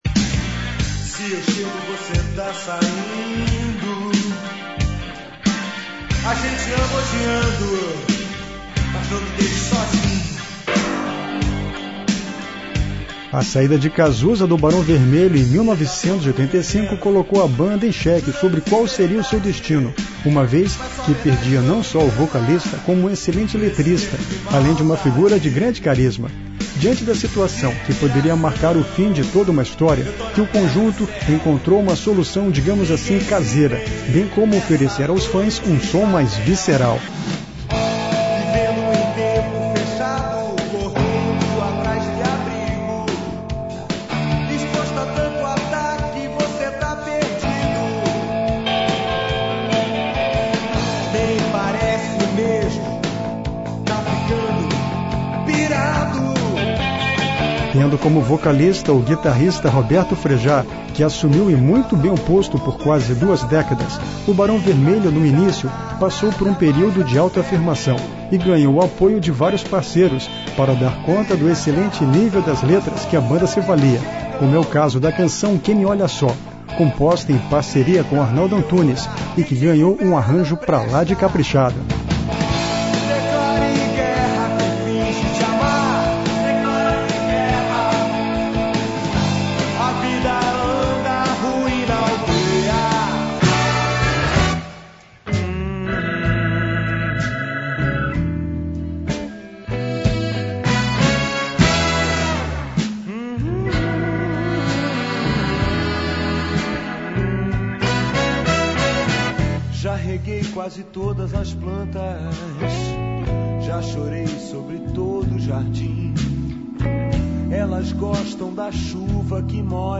A música  do Barão Vermelho sempre foi rock  ‘n’ roll,  porém isso nunca  impediu  que eles experimentassem outras  sonoridades  como o jazz, soul e até musica eletrônica .